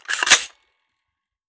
fps_project_1/5.56 M4 Rifle - Cocking Slide 003.wav at 909f84e6dd1d801cd5f05f67c0be0e69d4966c58